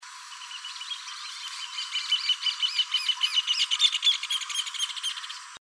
Hornero (Furnarius rufus)
Nombre en inglés: Rufous Hornero
Fase de la vida: Adulto
Localidad o área protegida: Reserva Ecológica Costanera Sur (RECS)
Condición: Silvestre
Certeza: Fotografiada, Vocalización Grabada